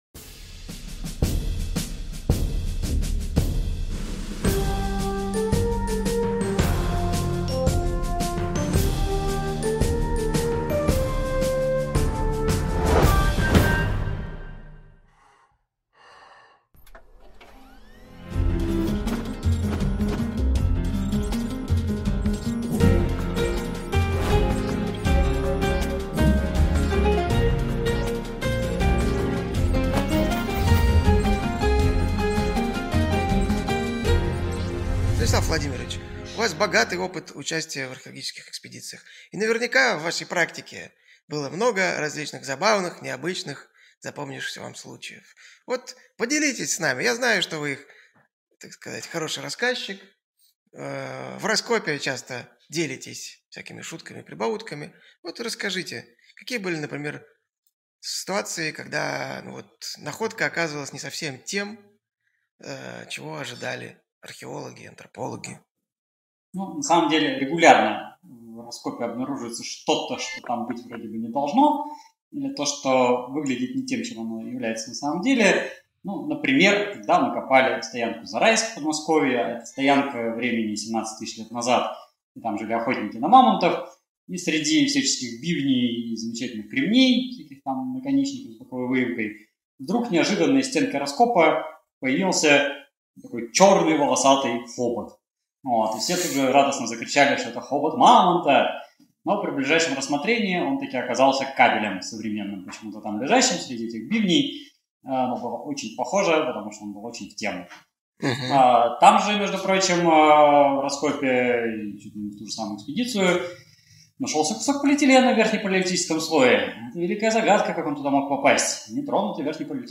Аудиокнига Байки археологов и антропологов | Библиотека аудиокниг